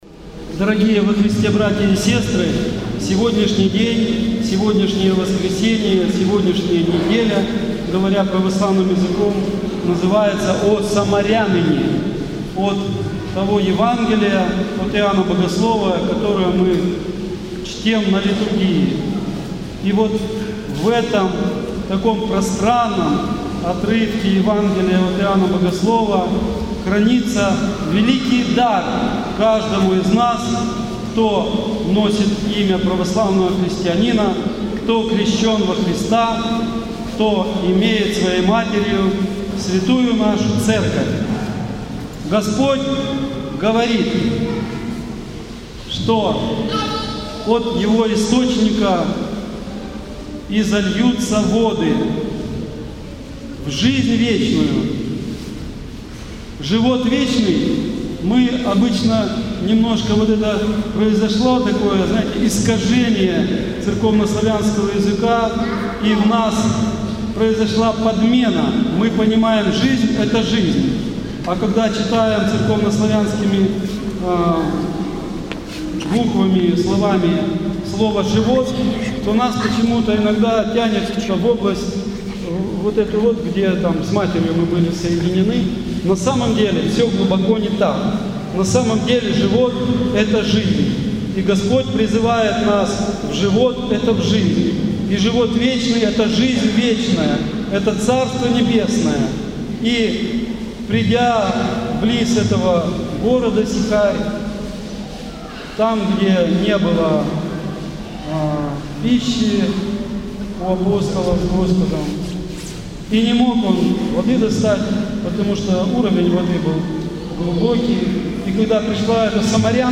Слово в неделю о самаряныне.